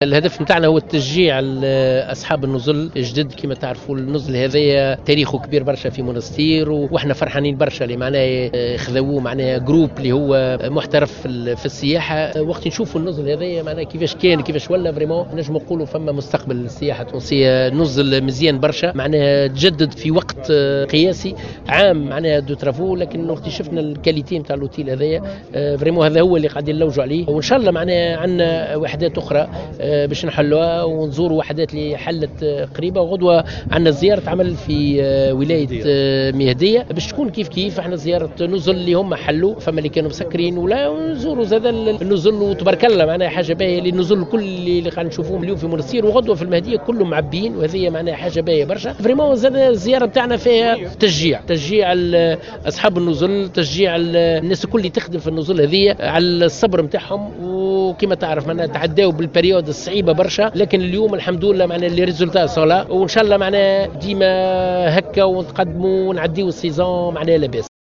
وجدد الطرابلسي، في تصريح للجوهرة أف أم، تشجيع الوزارة لأصحاب النزل وعمّال القطاع دفعا للسياحة في تونس، معلنا عن افتتاح وحدات فندقية إضافية خلال الفترة القادمة.